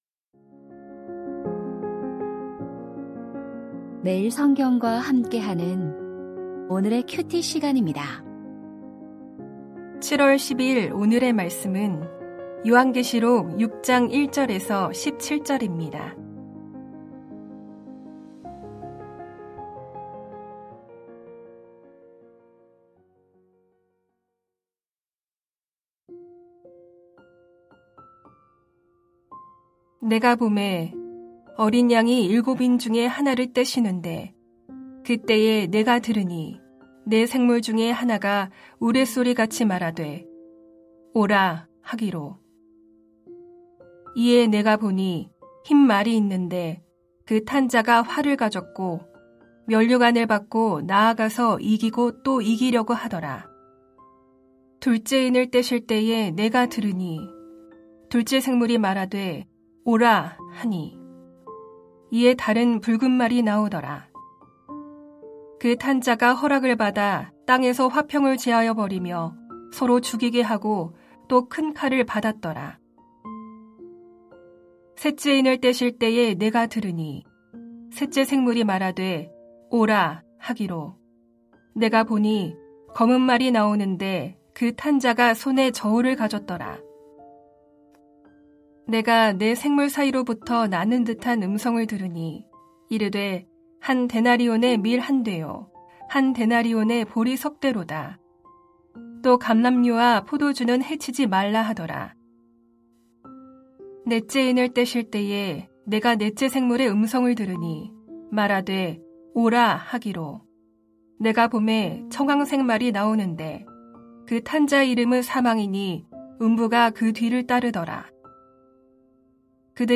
요한계시록 6:1-17 일곱 인 재앙과 하나님의 본심 2025-07-10 (목) > 오디오 새벽설교 말씀 (QT 말씀묵상) | 뉴비전교회